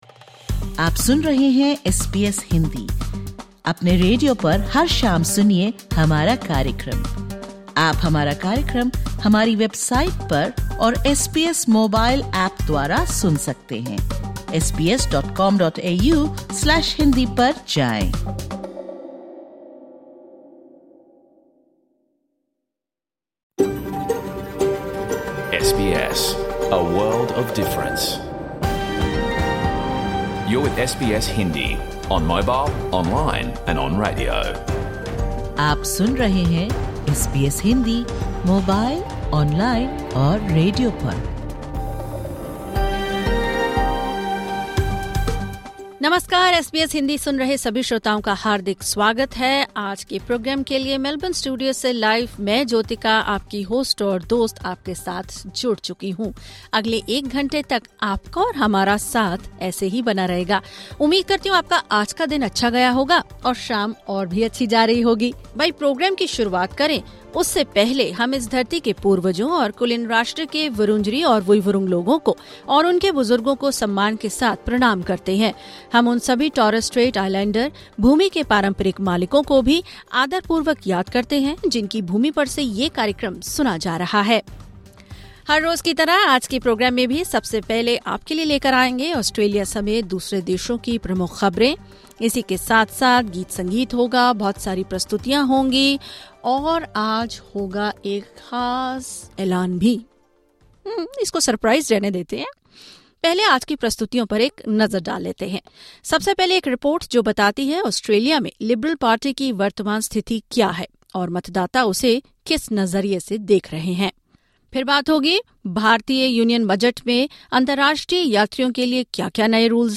Catch the full radio program of SBS Hindi